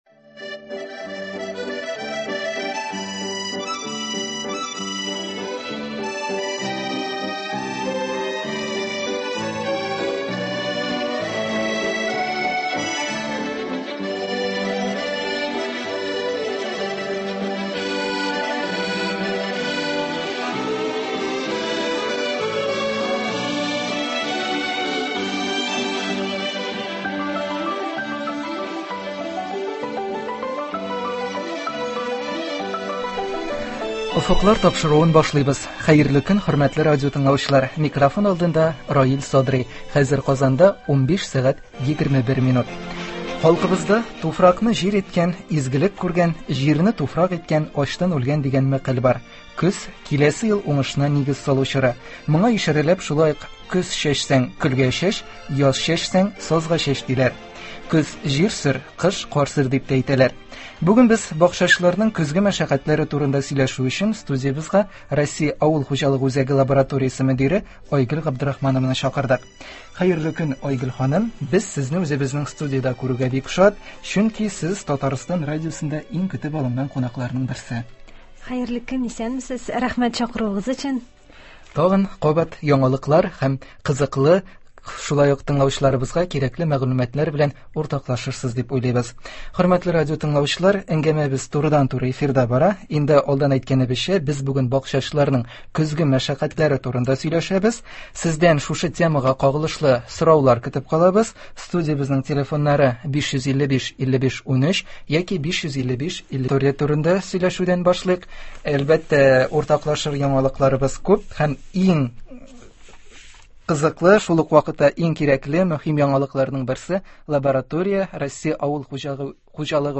Мондый шартларда шәхси бакчаларны кышкы чорга әзерләү үзенчәлекләре турында турыдан-туры эфирда
сөйли һәм тыңлаучылар сорауларына җавап бирә.